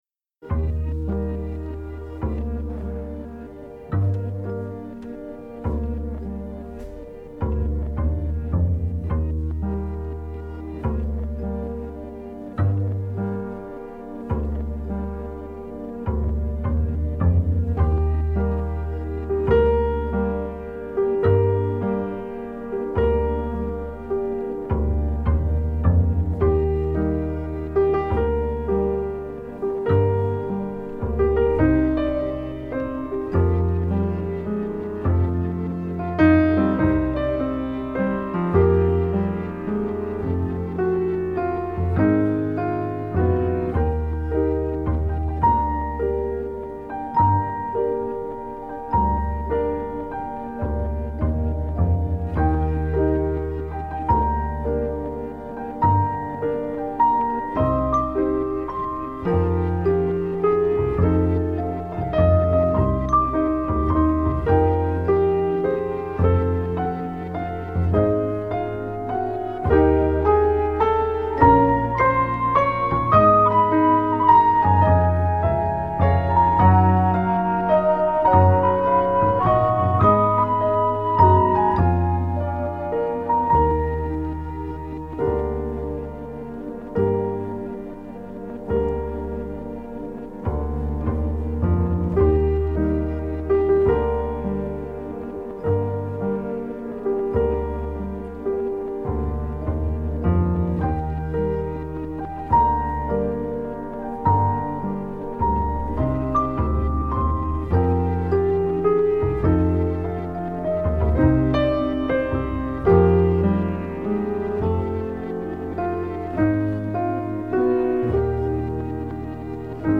спасибо за очень тонкий отзыв))) светлая грусть ;)
хороший кадр и музыка подобрана неплохо, немного навевает грусть и старые воспоминания